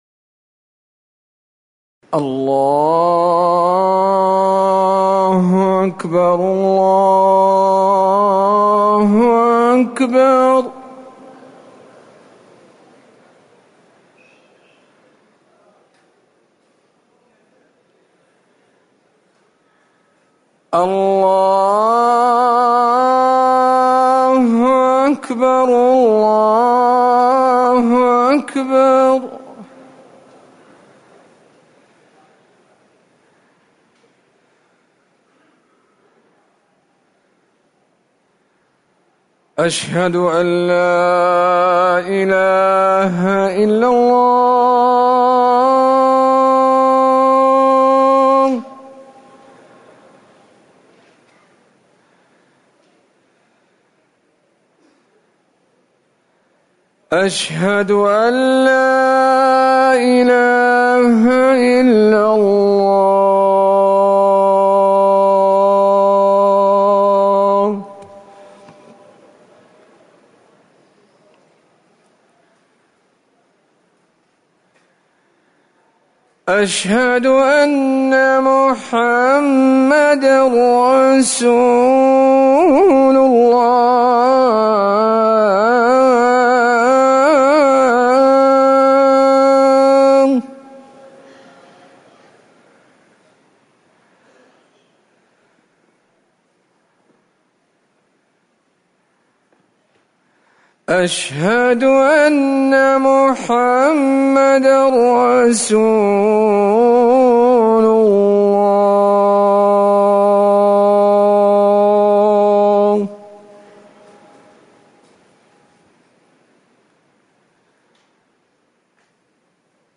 أذان العصر
تاريخ النشر ٥ صفر ١٤٤١ هـ المكان: المسجد النبوي الشيخ